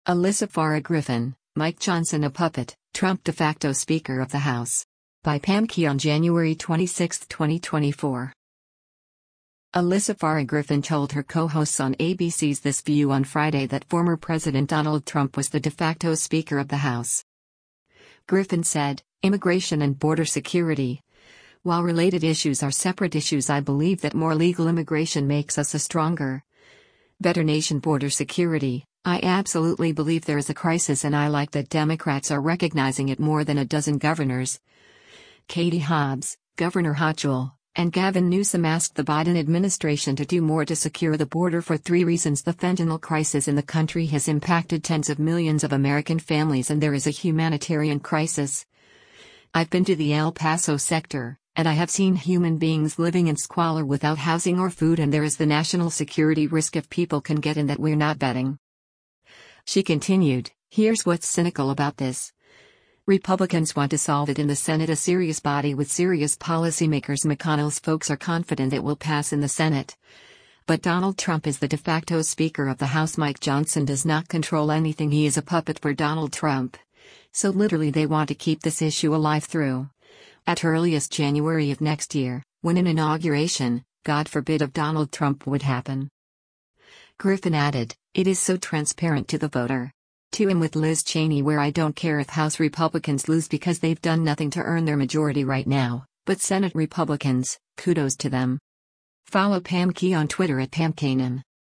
Alyssa Farah Griffin told her co-hosts on ABC’s “This View” on Friday that former President Donald Trump was the “de facto Speaker of the House.”